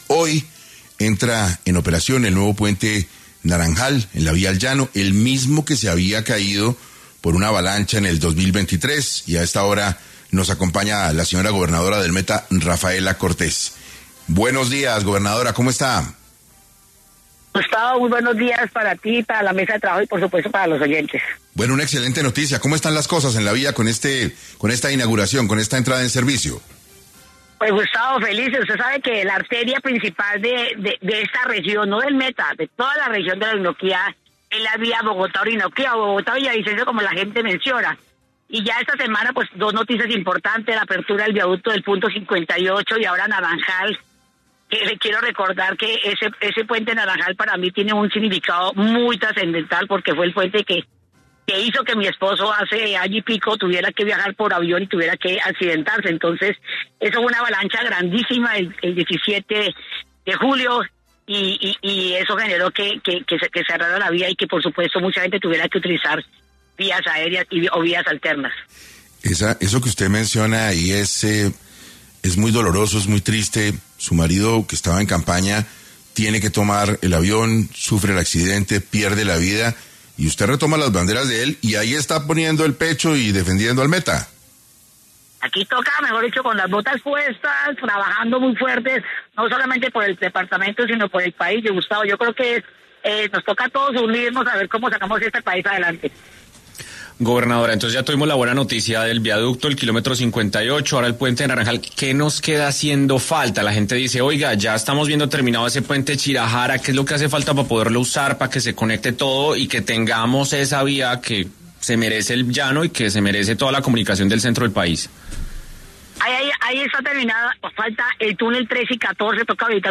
Rafaela Cortés, gobernadora del Meta, estuvo en 6AM para abordar cómo será la entrada en operación del nuevo puente Naranjal de la vía al Llano, que se habilita hoy, 14 de febrero.